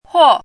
怎么读
huò
huo4.mp3